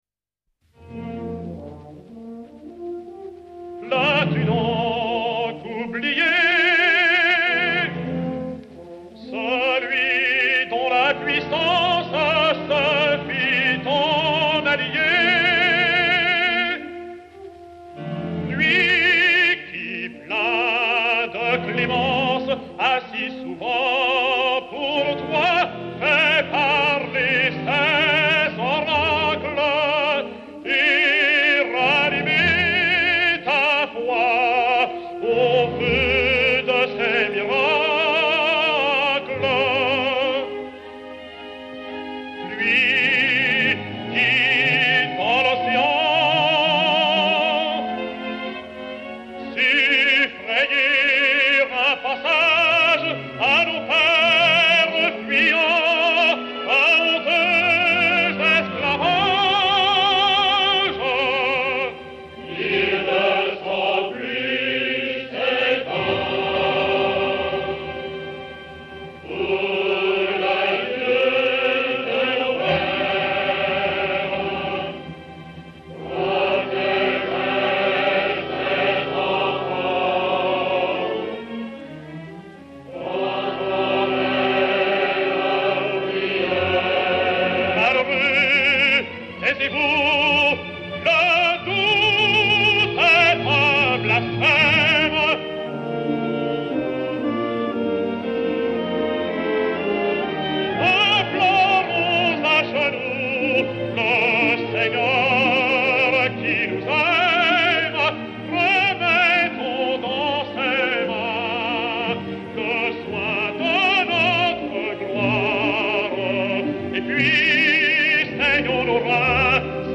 Choeurs et Orchestre